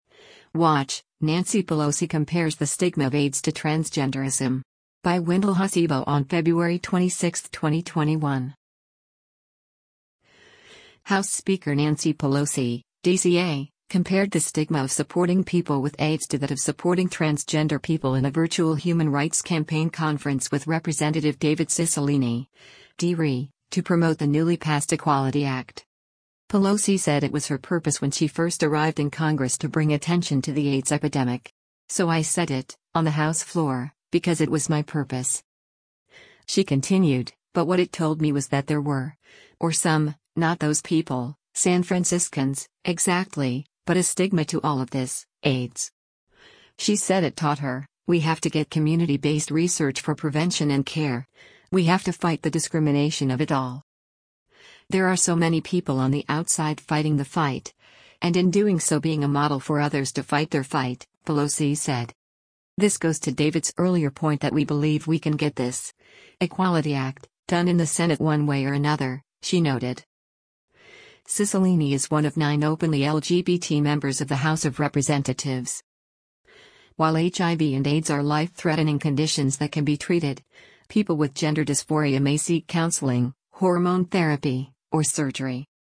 House Speaker Nancy Pelosi (D-CA) compared the “stigma” of supporting people with AIDS to that of supporting transgender people in a virtual Human Rights Campaign conference with Representative David Cicilline (D-RI) to promote the newly passed Equality Act.